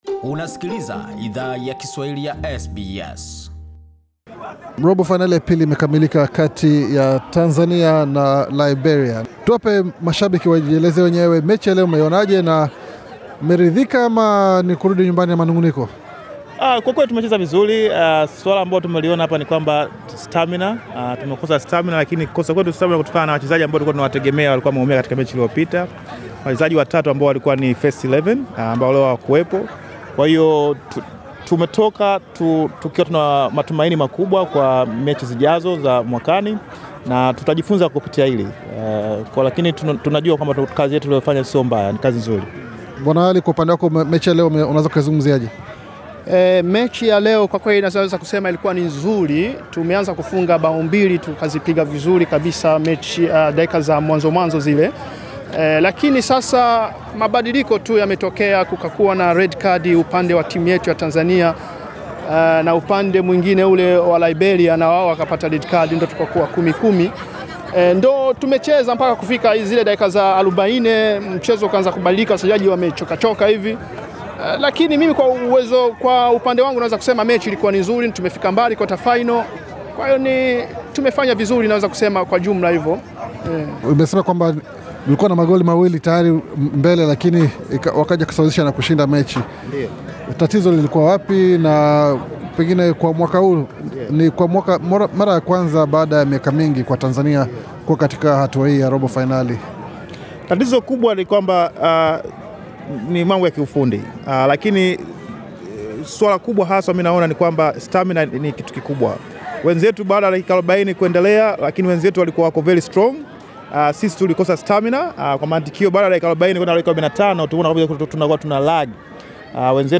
SBS Swahili ilizungumza na mashabiki pamoja na wachezaji na wakufunzi wa timu ya Tanzania punde baada ya mechi hiyo kukamilika.